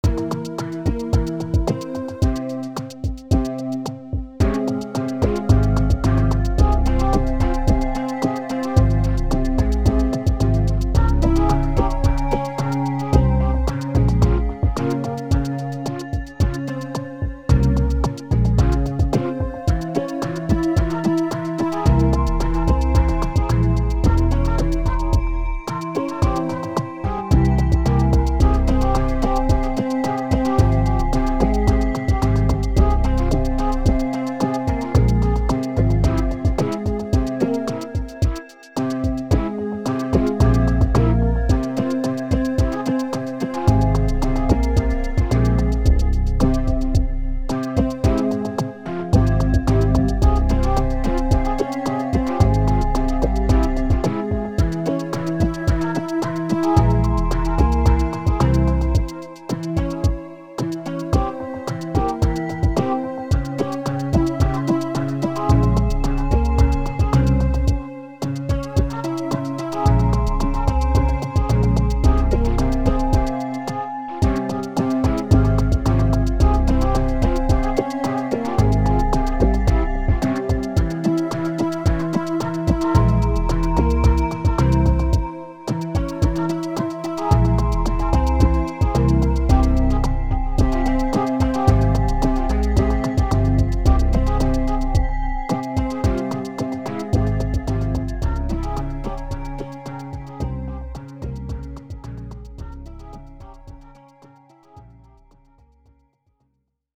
Voici 3 ambiances musicales pour illustrer les capacités de la Société Henon.